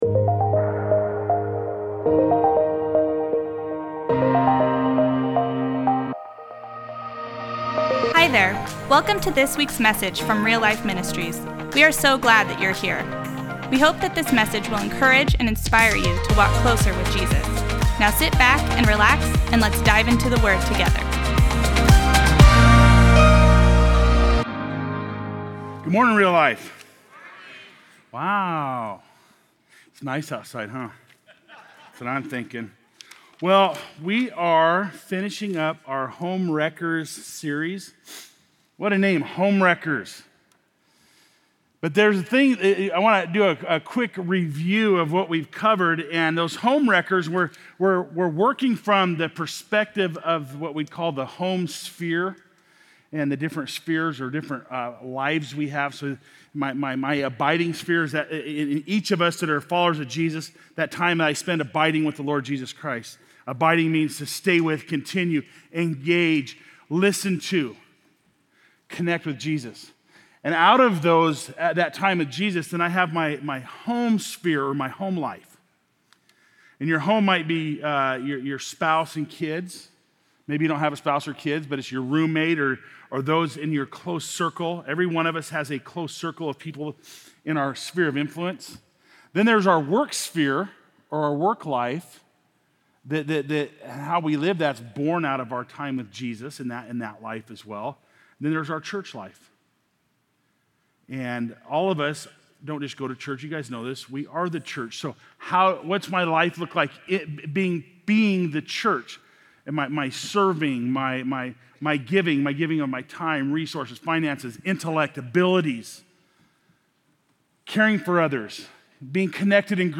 Post Falls Campus